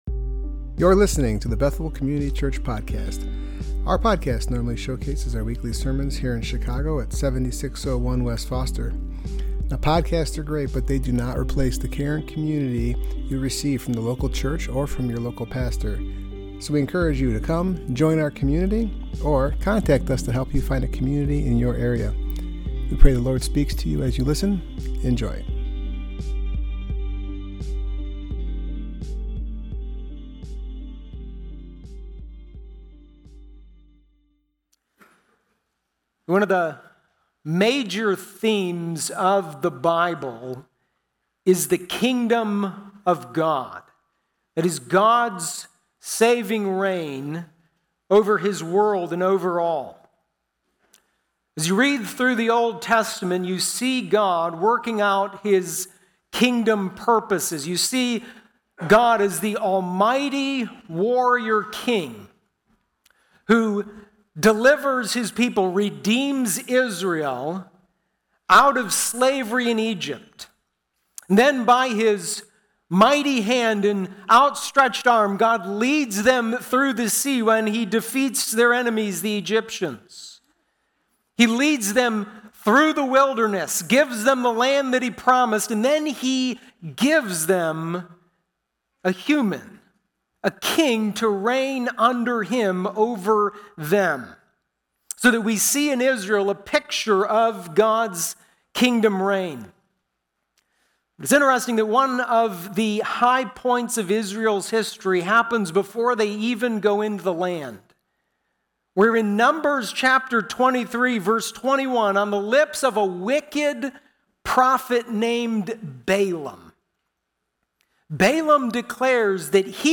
Service Type: Worship Gathering